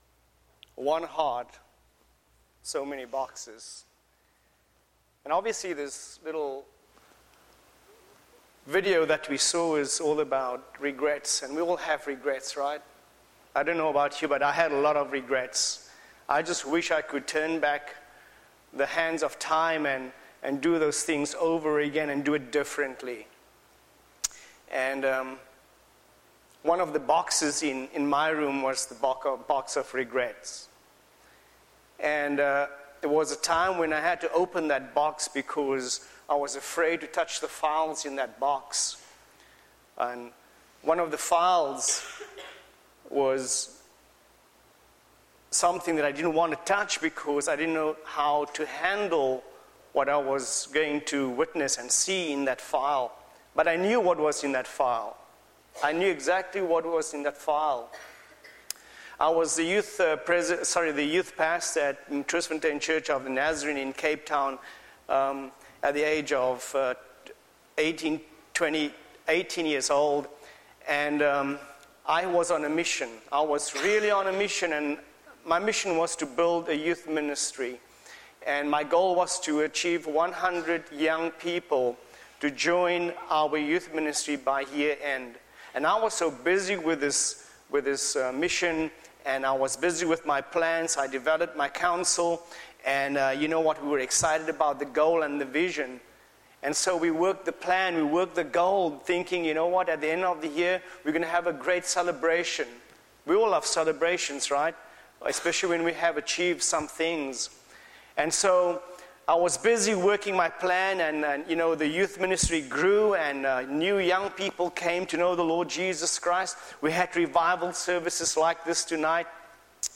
Wednesday Evening Revival